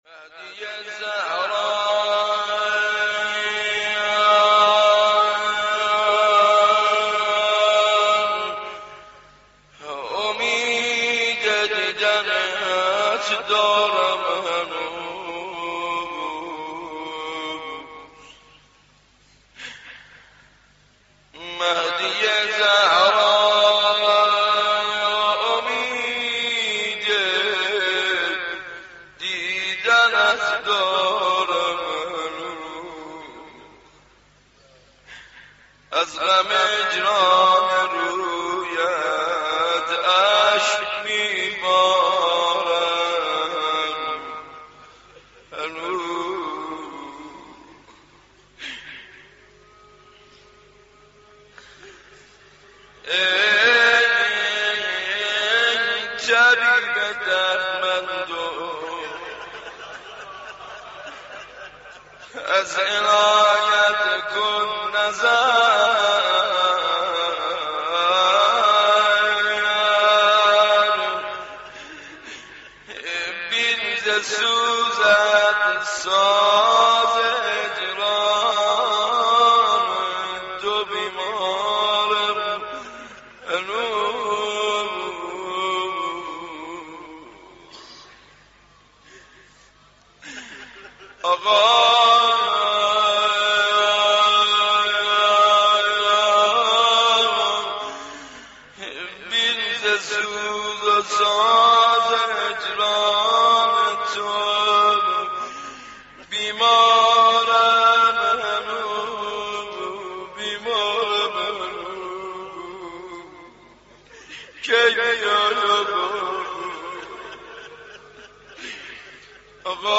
مداحی شنیدنی